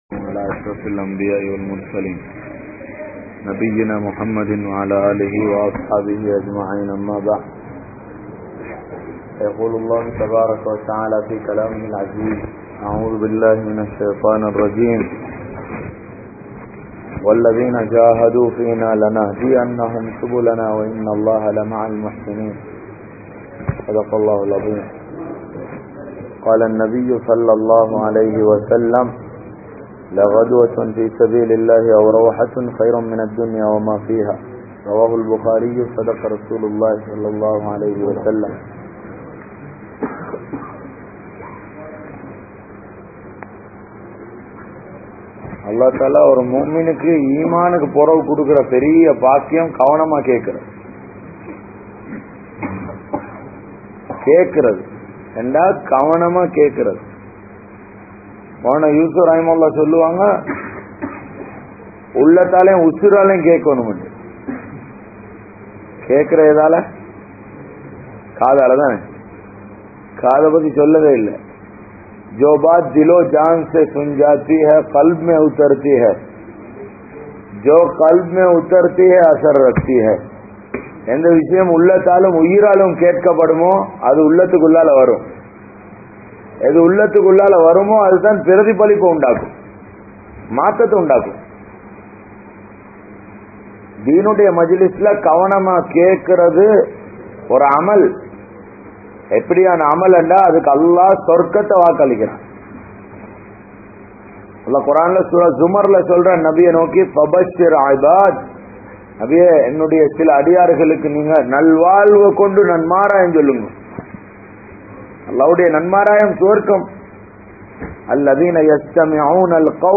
Dhauwaththin Avasiyam (தஃவத்தின் அவசியம்) | Audio Bayans | All Ceylon Muslim Youth Community | Addalaichenai